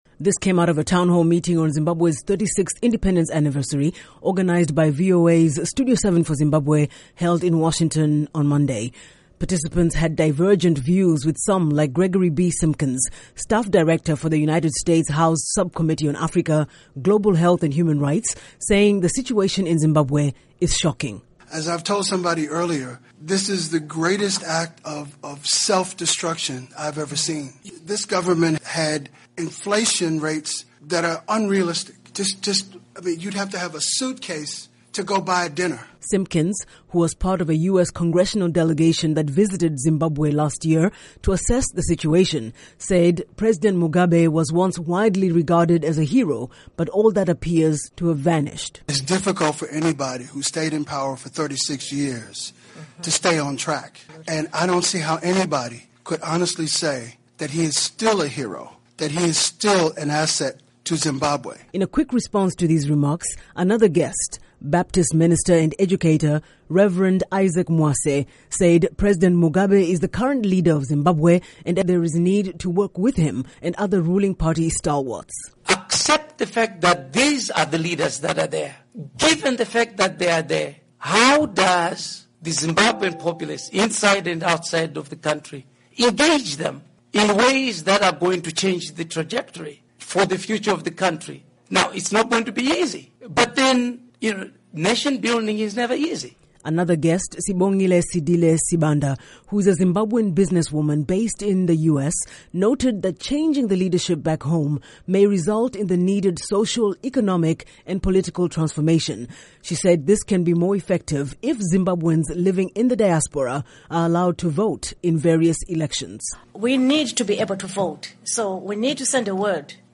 A town hall discussion organized and hosted by VOA’s Zimbabwe Service in Washington, titled, Zimbabwe @ 36: The Way Forward, revealed deep-seated concerns among those in the diaspora, about their country’s current state.